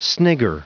Prononciation du mot snigger en anglais (fichier audio)
Prononciation du mot : snigger